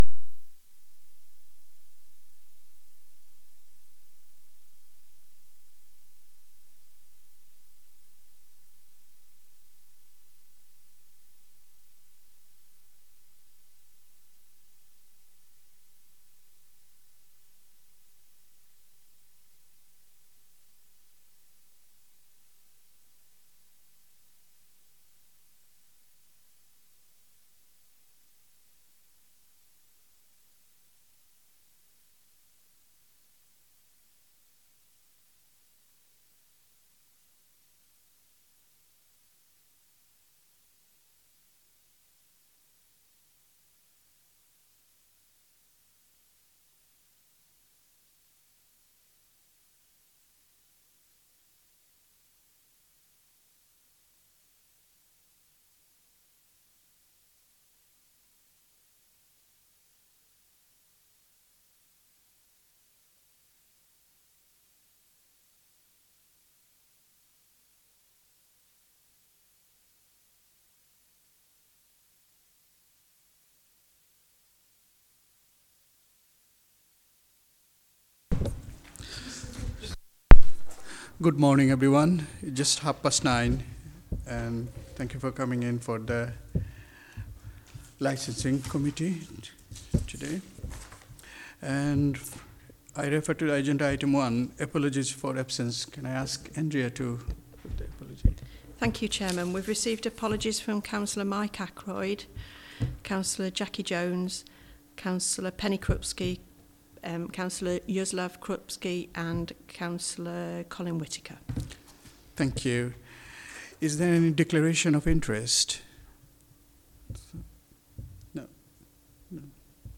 Committee Licensing Committee Meeting Date 04-06-24 Start Time 9.30am End Time 9.40am Meeting Venue Coltman VC Room, Town Hall, Burton upon Trent Please be aware that not all Council meetings are live streamed.
Meeting Recording 240604.mp3 ( MP3 , 9.85MB )